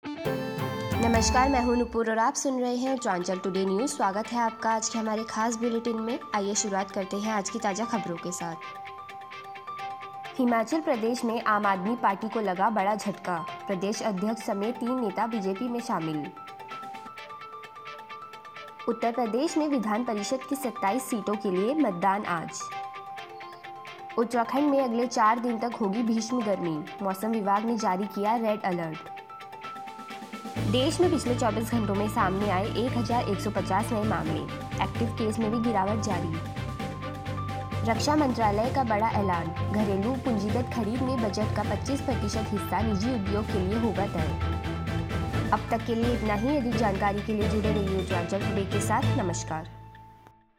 फटाफट समाचार(9-4-2022) सुनिए अब तक की कुछ ख़ास खबरे